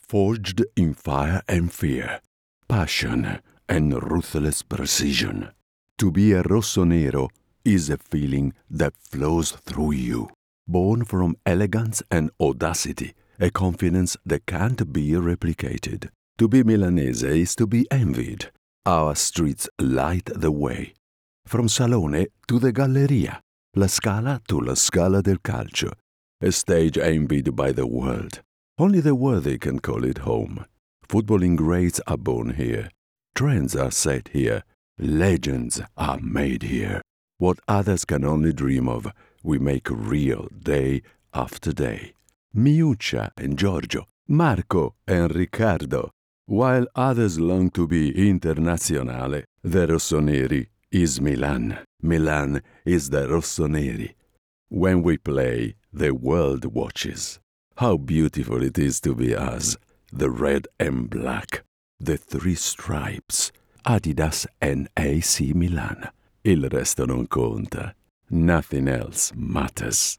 Italian - Male
Great versatility, from deep, warm and velvety to high pitched cartoon warbles from sexy to dark and scary.
Commercial, Bright, Upbeat, Conversational